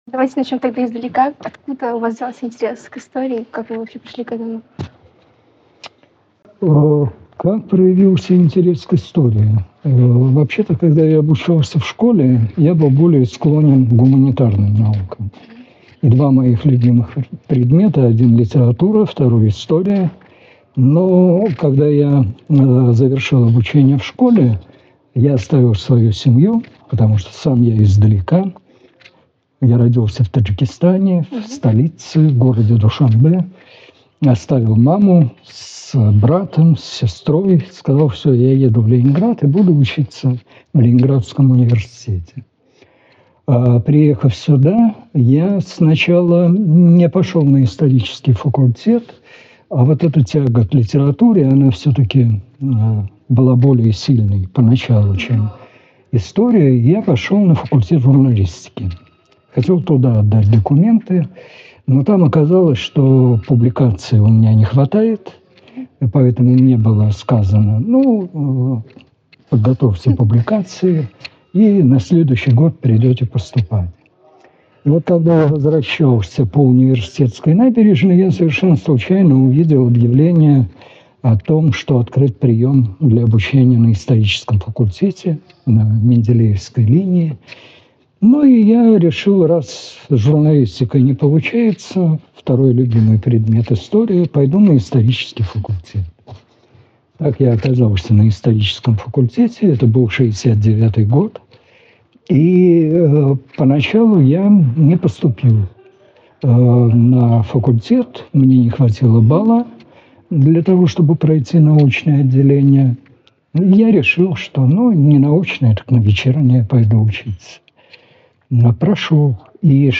Устные воспоминания универсантов ЛГУ 1960–1980-х гг. в виртуальном пространстве